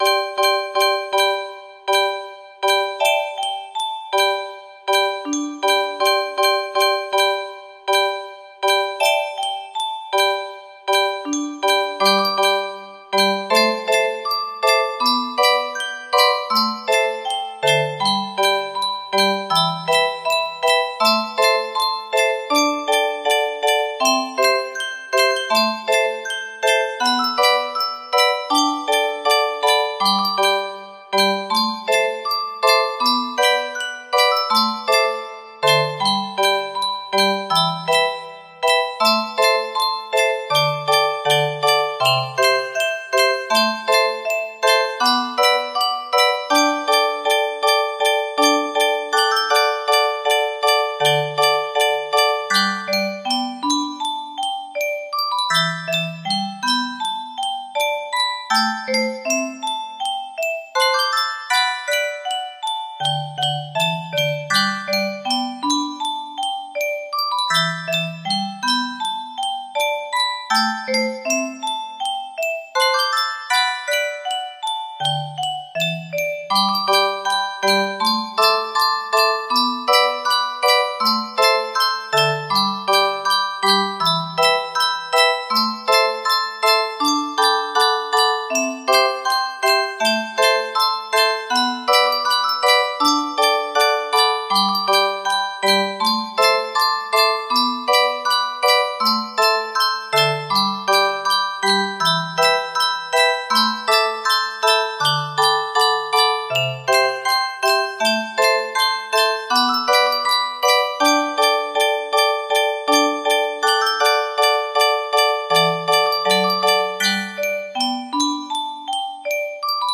Full range 60
Imported from a midi file.